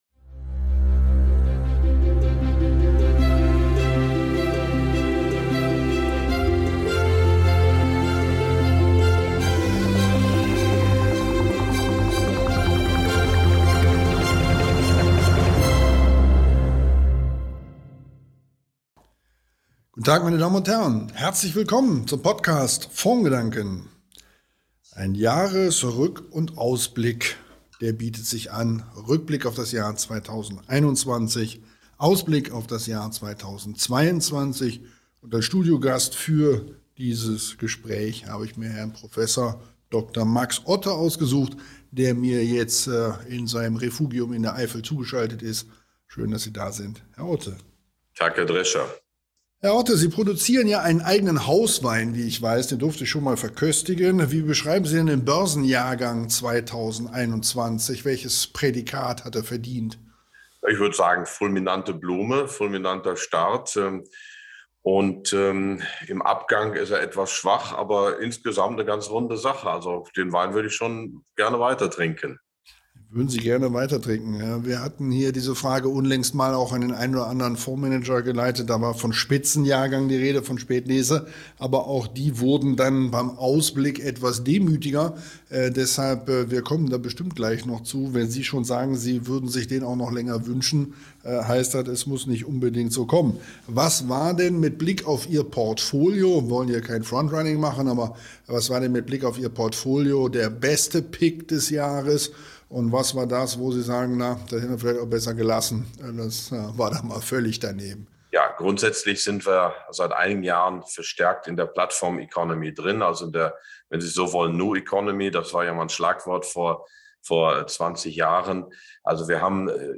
Im Gespräch mit dem Fondsmanager und Wirtschaftswissenschaftler Prof. Dr. Max Otte.